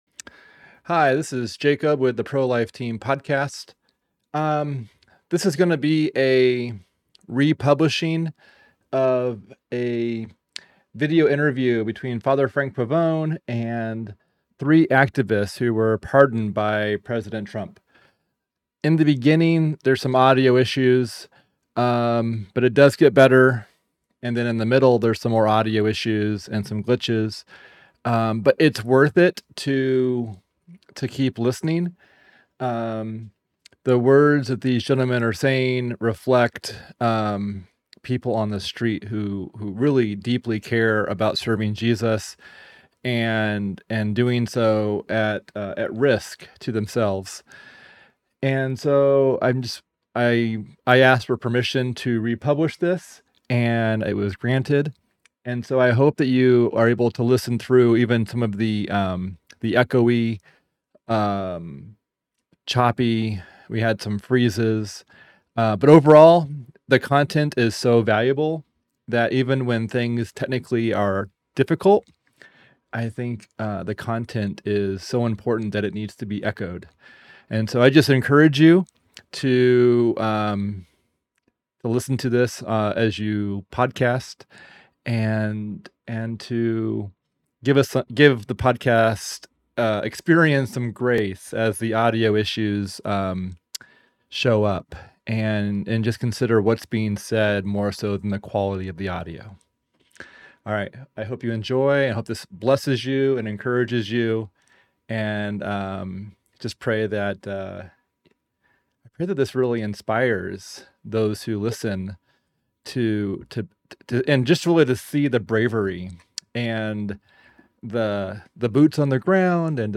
interviewing three pro-life activists